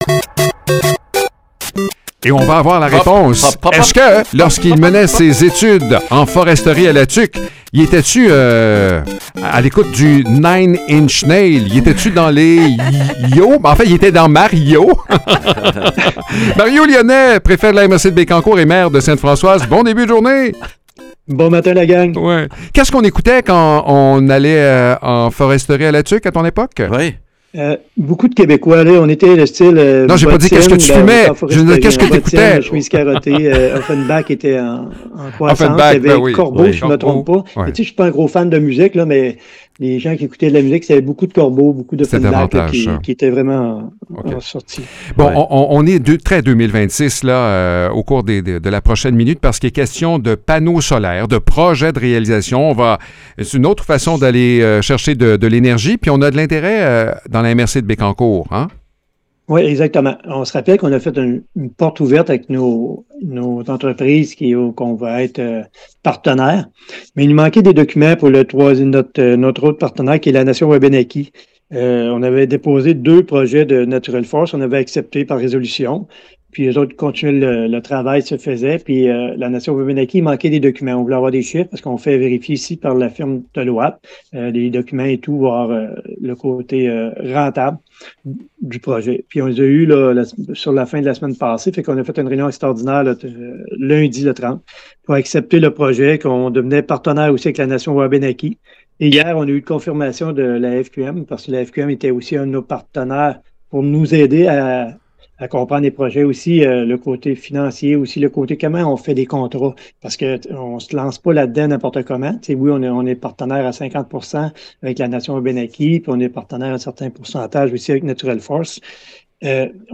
Mario Lyonnais, maire de Sainte-Françoise et préfet de la MRC de Bécancour, nous fait le point sur un projet de panneaux solaires qui progresse bien.